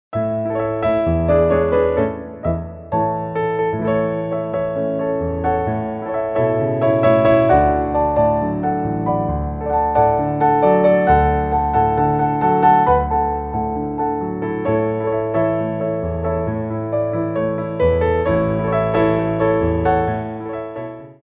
Tendus
4/4 (8x8)